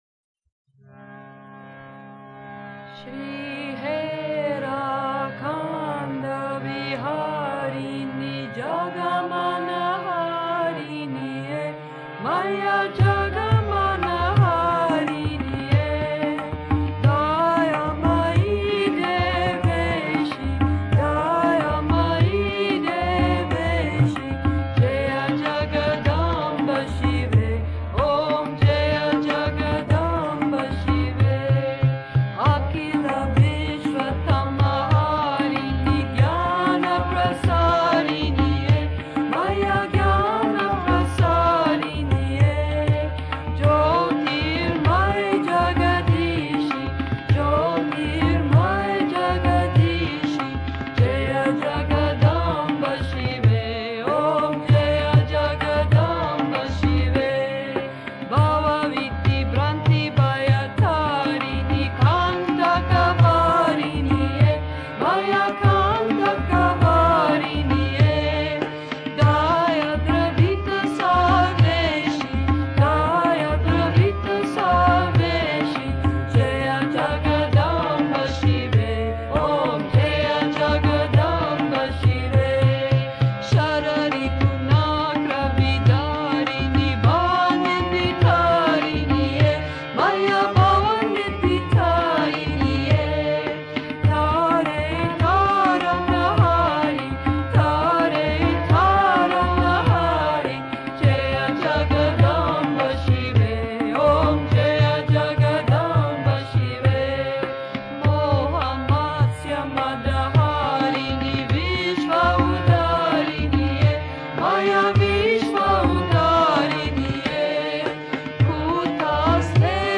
巴巴吉火典旋律: